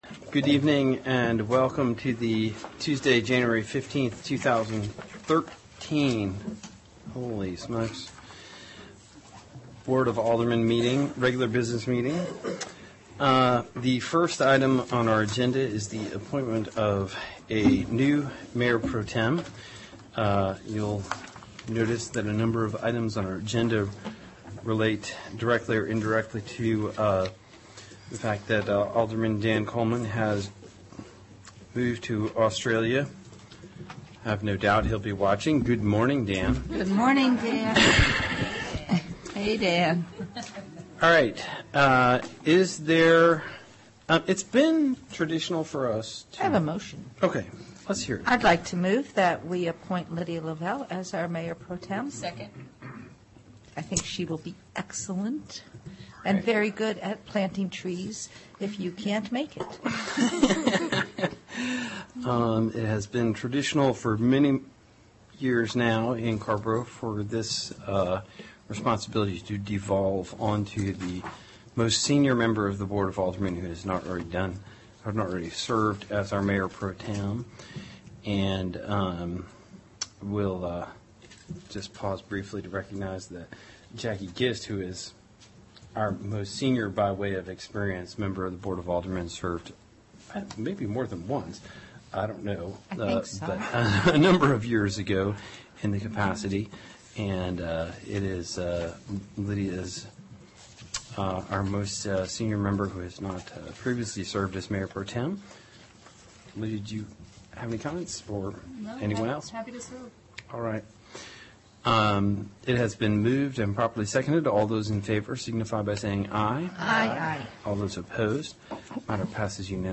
AGENDA CARRBORO BOARD OF ALDERMEN REGULAR MEETING* Tuesday, January 15, 2013 7:30 P.M., TOWN HALL BOARD ROOM